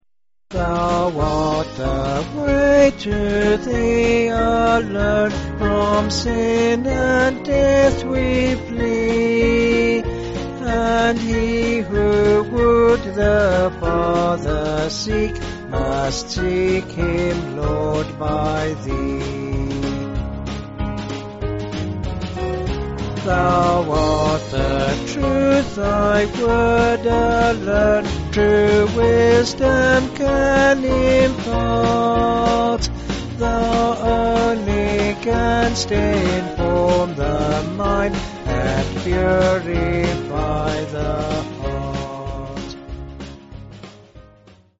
(BH)   4/Db-D
Vocals and Band
262.6kb Sung Lyrics 1.6mb